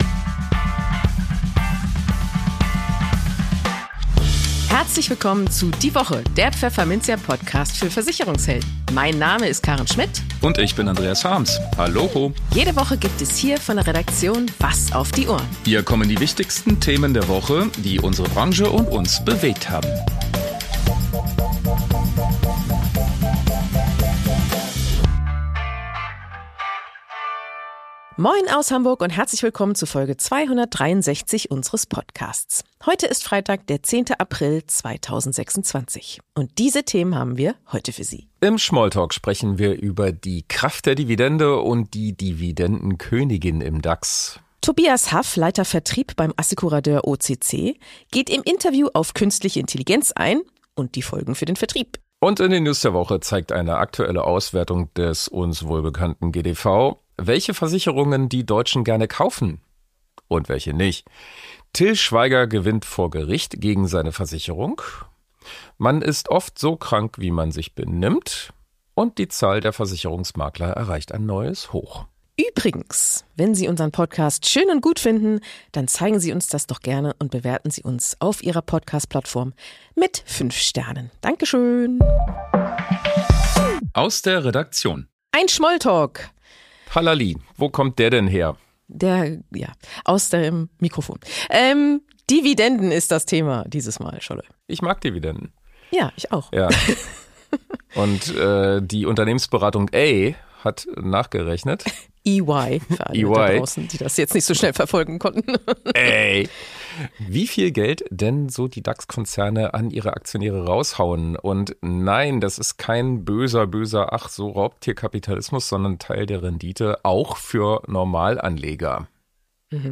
geht im Interview auf künstliche Intelligenz ein und die Folgen für den Vertrieb. Ab 00:16:44: Und in den News der Woche zeigt eine aktuelle Auswertung des GDV, welche Versicherungen die Deutschen gerne kaufen – und welche nicht.